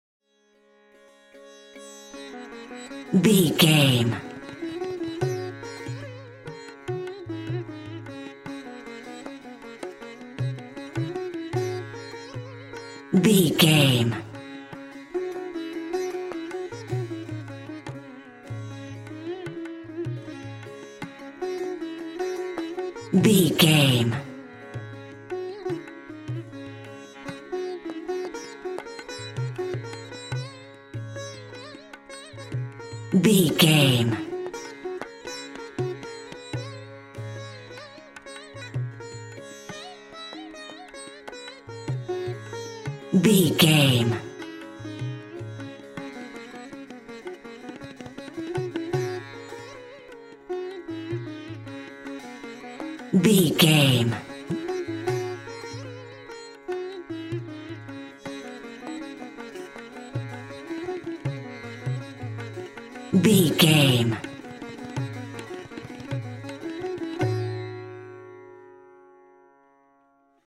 Mixolydian
D♭
Fast
World Music
percussion